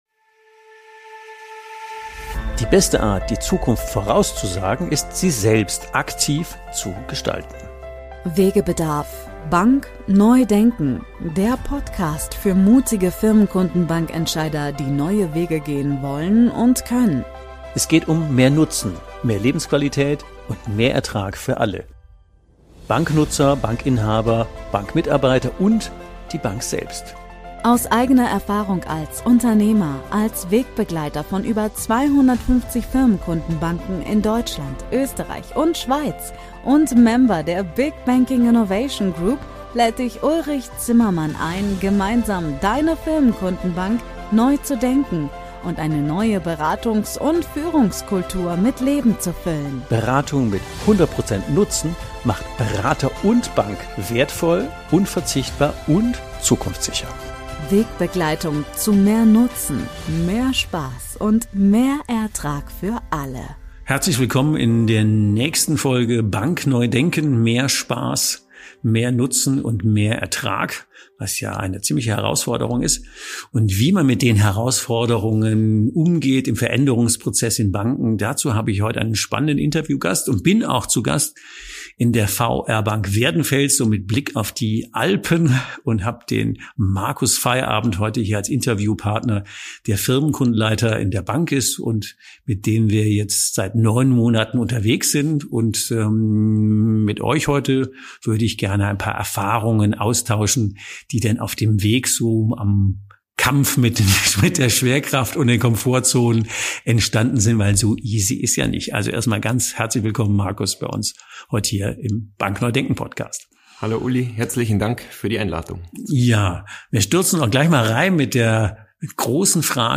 Im Gespräch erörtern wir einige Aspekte und Herausforderungen auf dem Weg in eine neue Strategie.